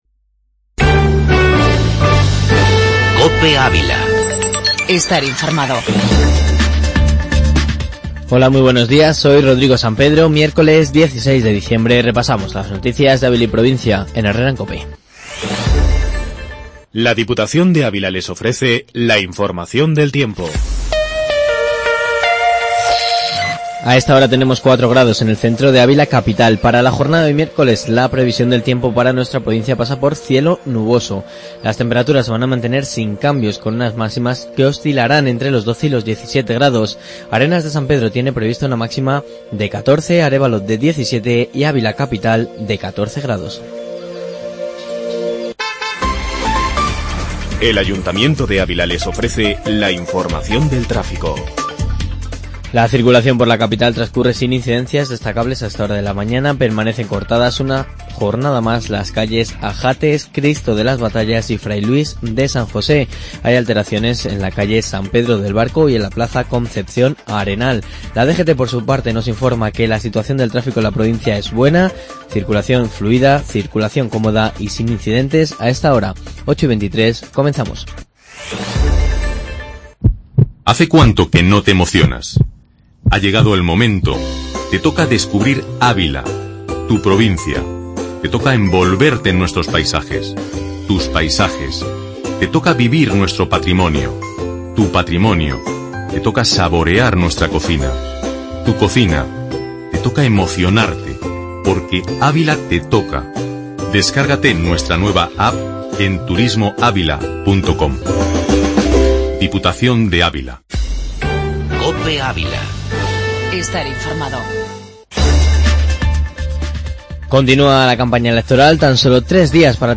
Informativo matinal en 'Herrea en Cope'.